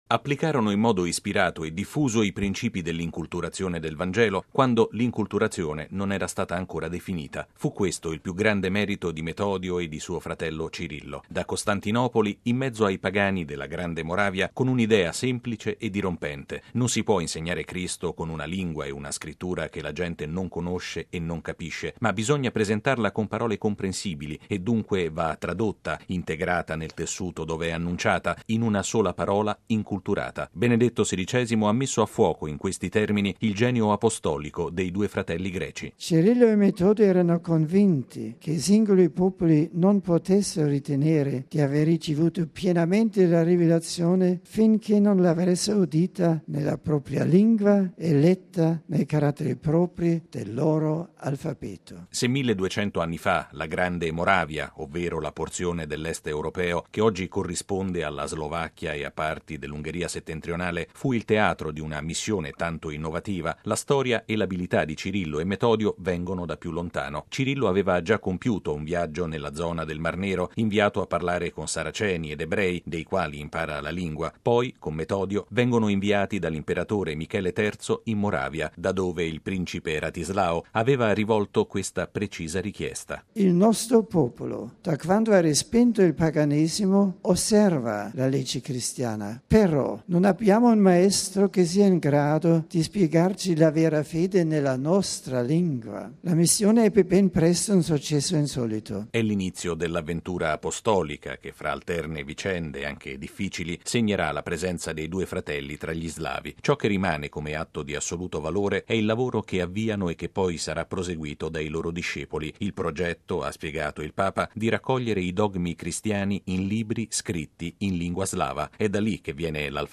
◊   I due apostoli dell’Oriente cristiano, i compatroni d’Europa Cirillo e Metodio, e la loro straordinaria esperienza di evangelizzazione condotta nel mondo slavo del nono secolo, sono stati al centro della catechesi di Benedetto XVI, all’udienza generale di questa mattina in Piazza San Pietro, tenuta dal Papa di fronte a 40 mila persone.